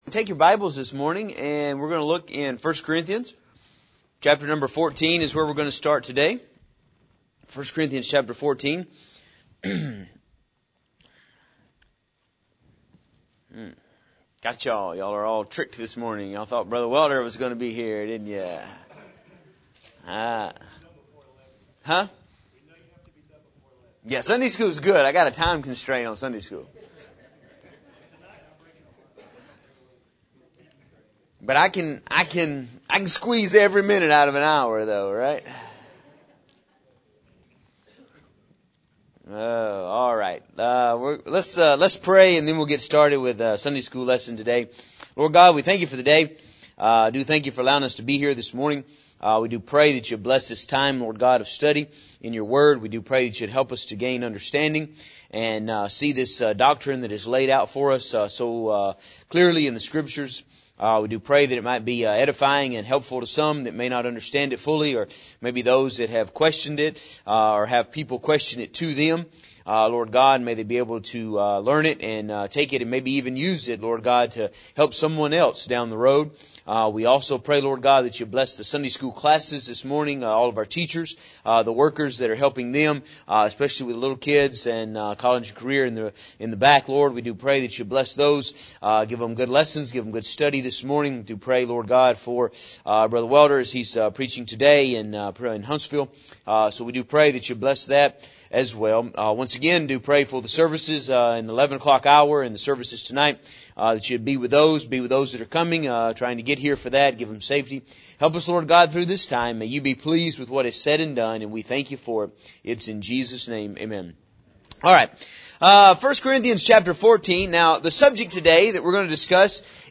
In this lesson we are going to look at the subject of tongues from the Bible. The word of God will show that tongues are a gift and a language. They are not essential to having the Holy Ghost as many teach today.